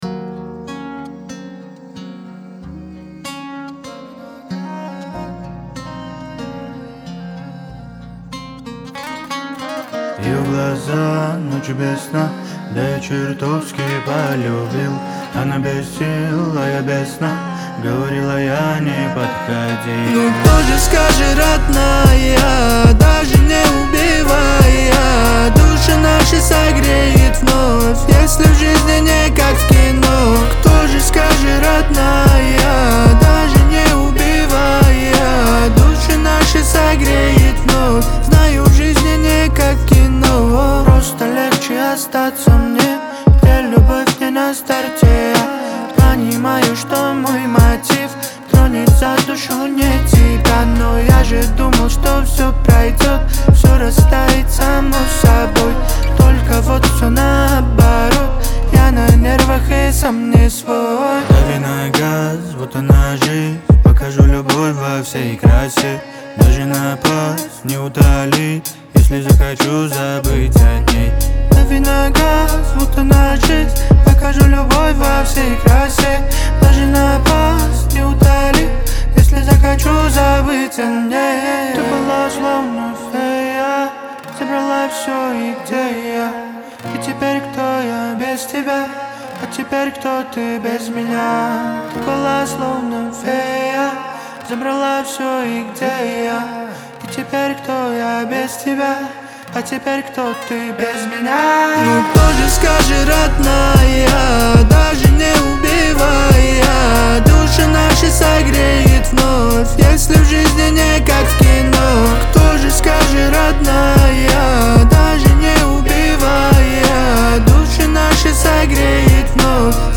Трек размещён в разделе Русские песни / Альтернатива.